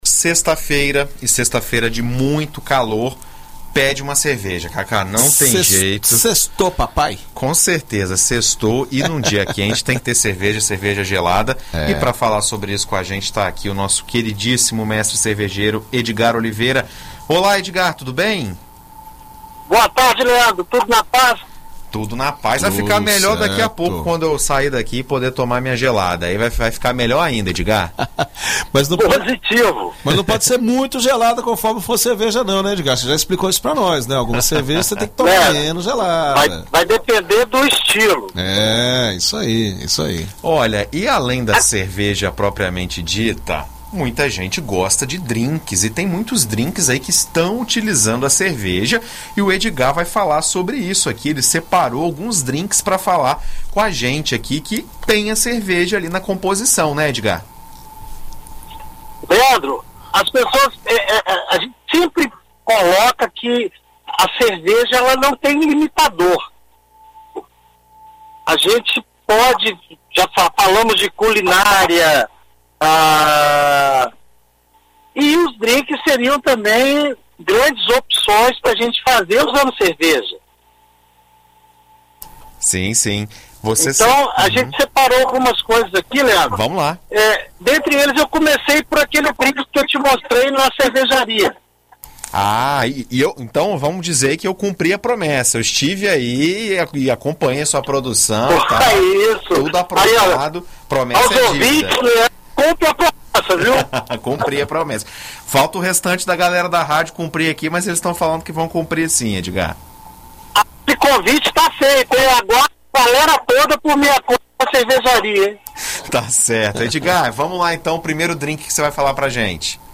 Em entrevista à BandNews FM Espírito Santo nesta sexta-feira